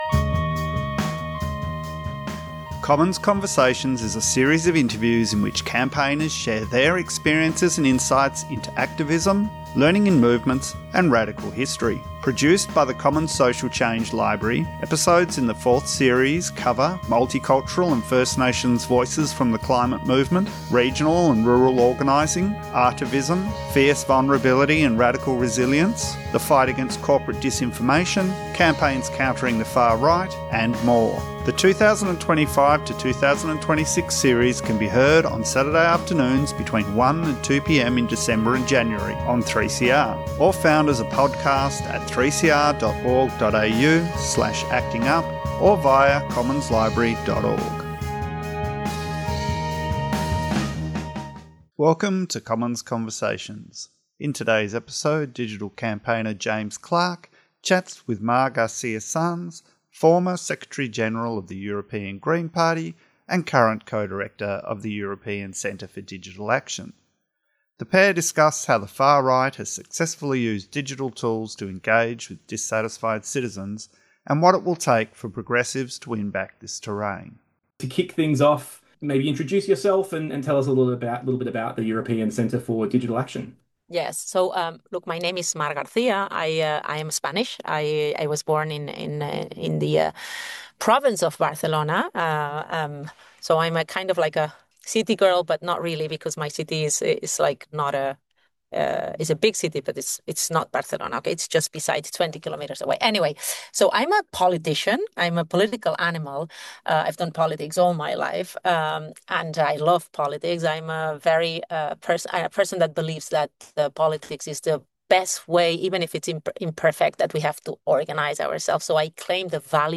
Commons Conversations is a series of interviews in which campaigners share their experiences and insights into activism, learning in movements, radical history, and more.